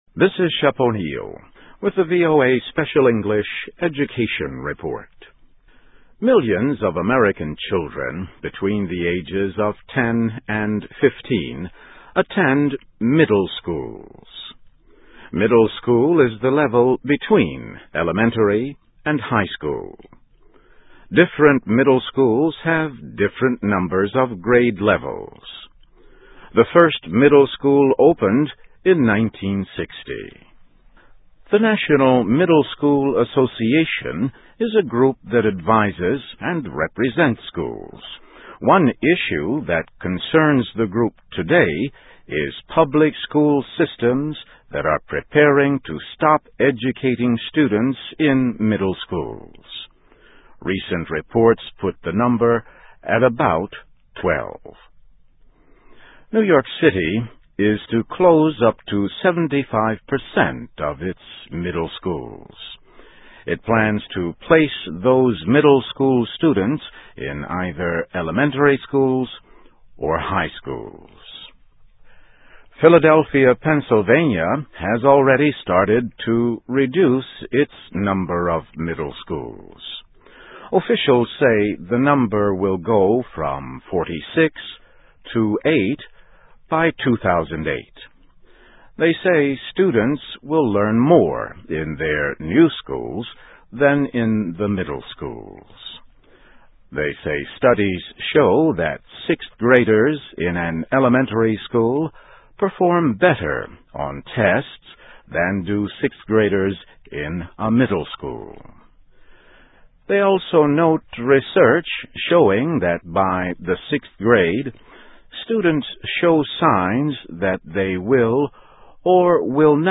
se-ed-middle-school.mp3